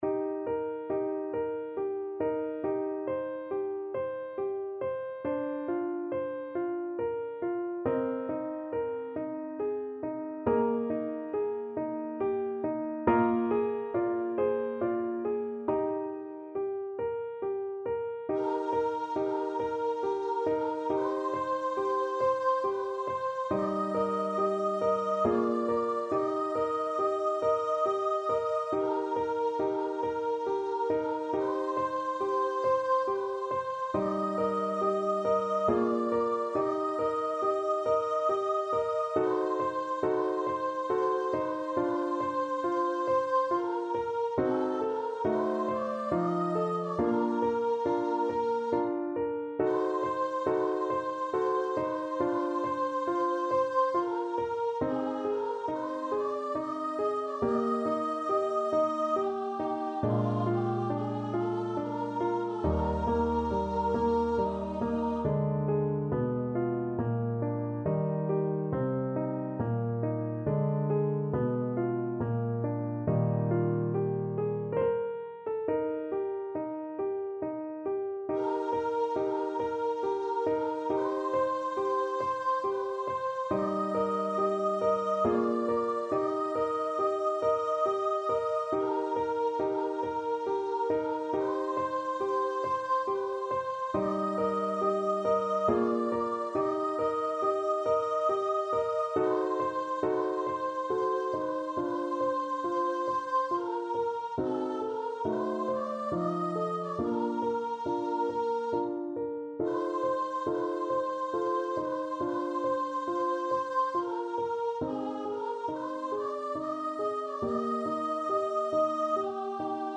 Voice
3/8 (View more 3/8 Music)
Eb major (Sounding Pitch) (View more Eb major Music for Voice )
= 69 Langsam
Classical (View more Classical Voice Music)